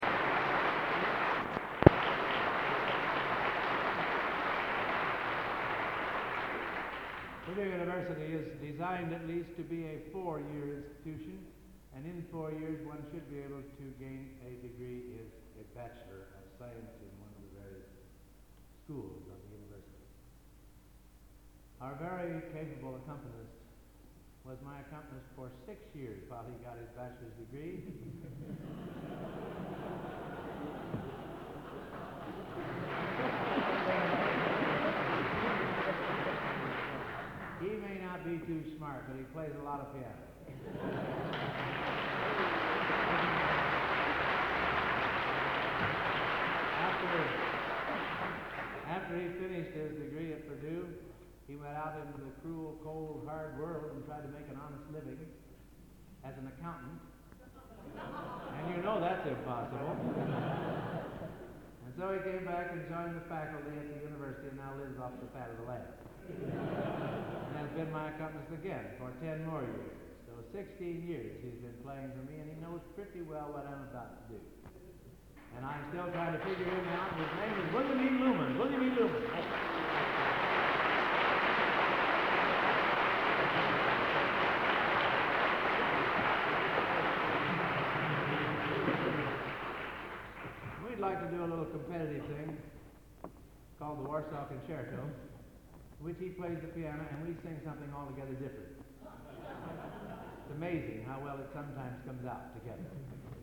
Location: Plymouth, England
Genre: | Type: Director intros, emceeing